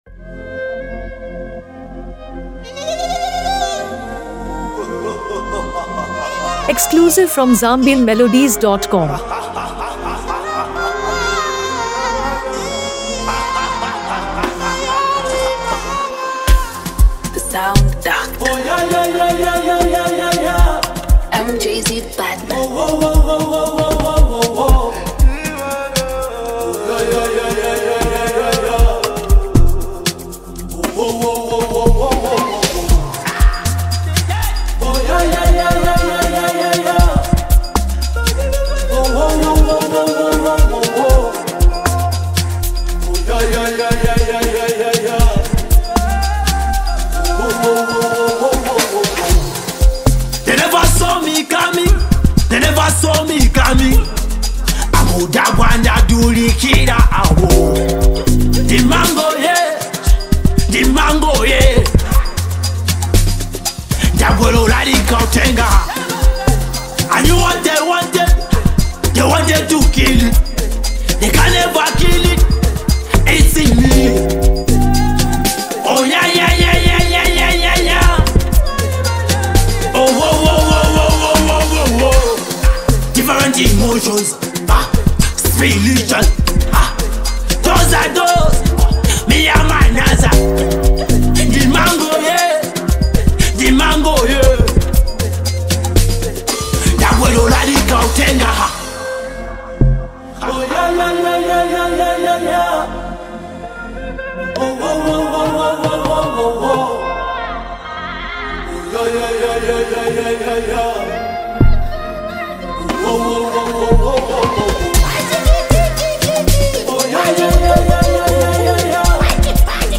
a powerful motivational anthem